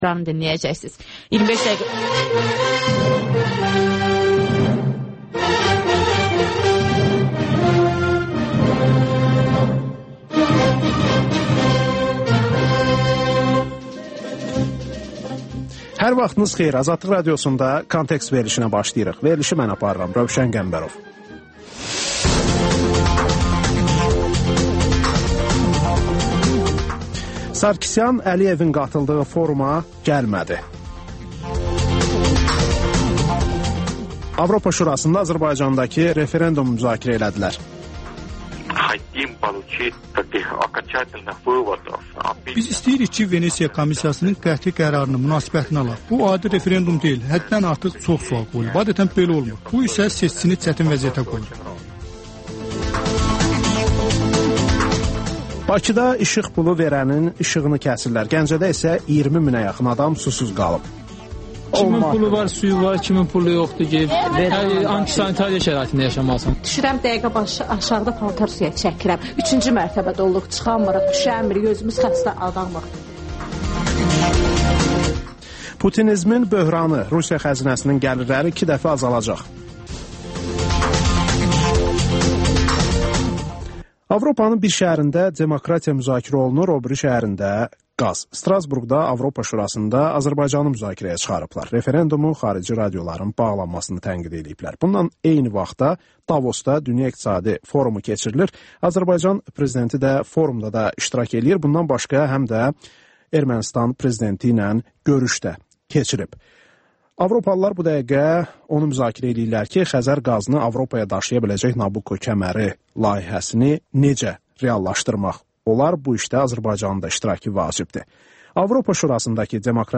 Müsahibələr, hadisələrin müzakirəsi, təhlillər (Təkrar)